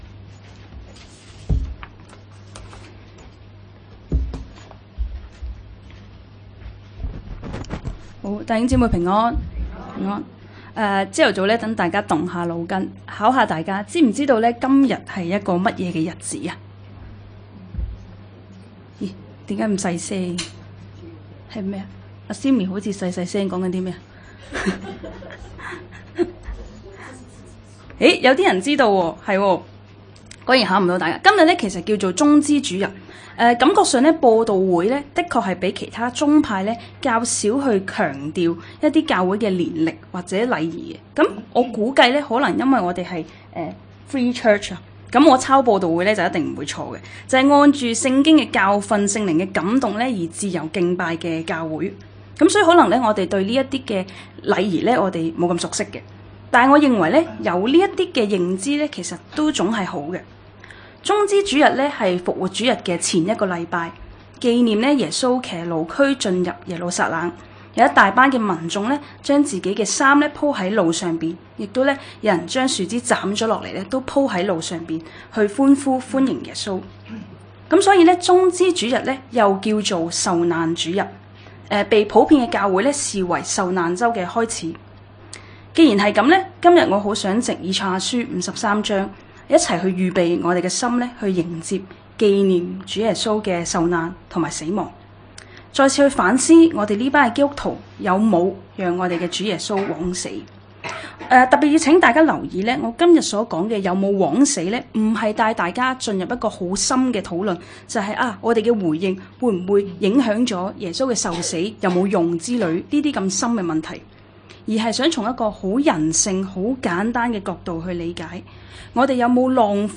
講道 ：別讓祂枉死